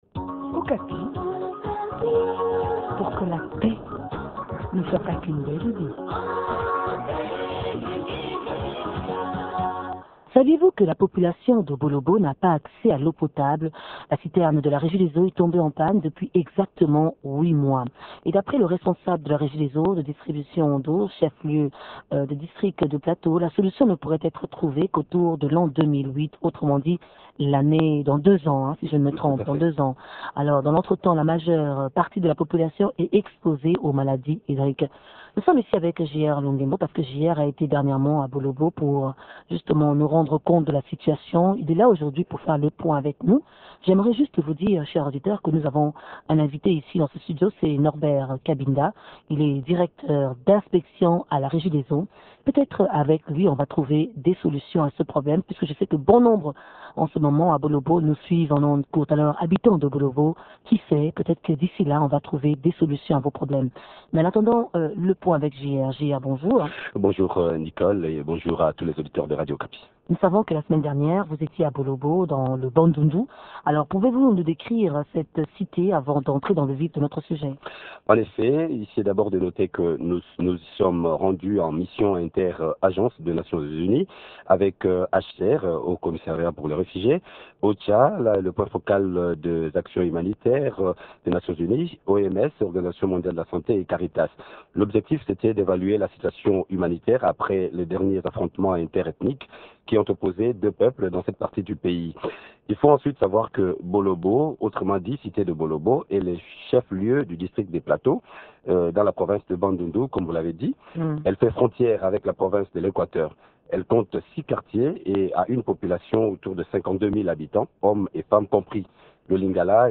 L’essentiel de l’entretien dans cet élément.